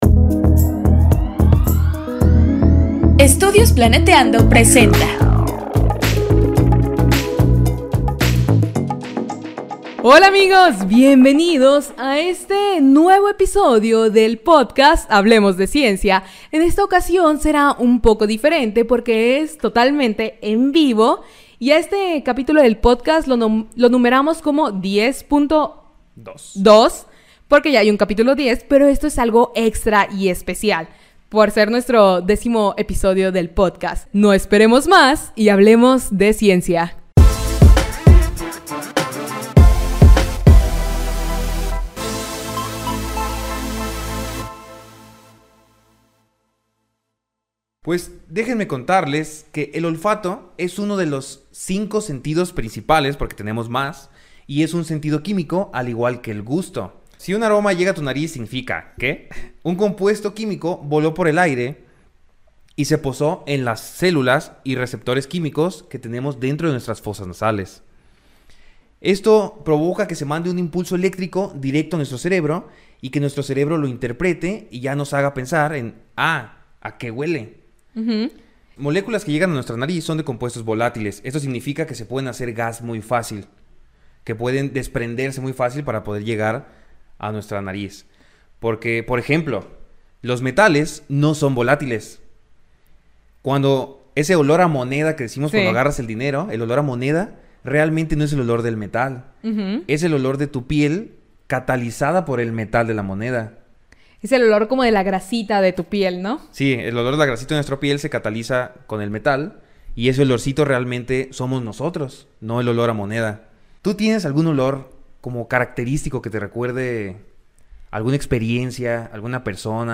Escucha un resumen de lo que sucedió en el en vivo.